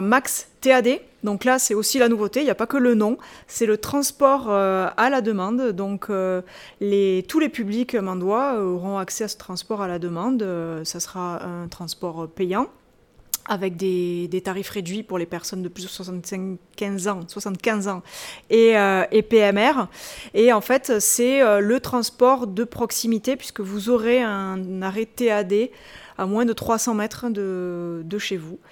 Aurélie Maillol, conseillère municipale mobilité à la ville de Mende.